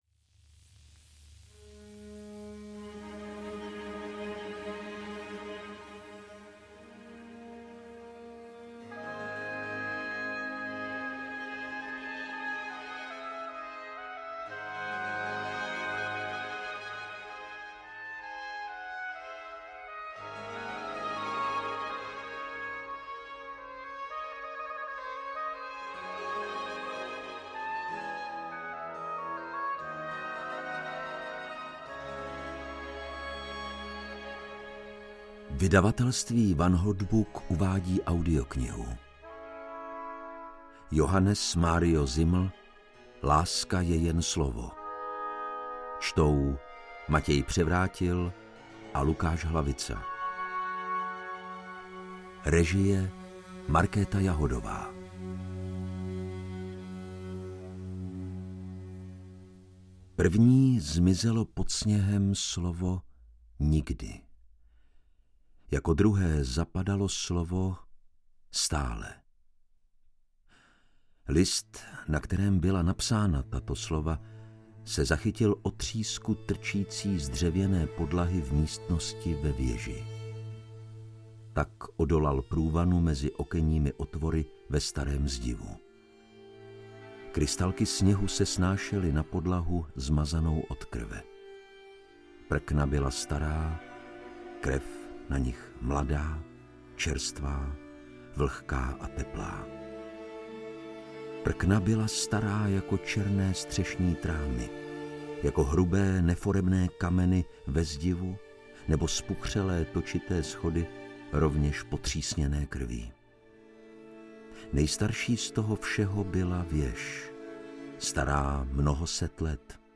Audiobook
Read: Lukáš Hlavica